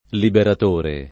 liberat1re] s. m.; f. ‑trice — es.: l’arrivo degli eserciti liberatori [l arr&vo del’l’ e@$r©iti liberat1ri] — sim. il pers. m. stor. Liberatore e i cogn. Liberatore, Liberatori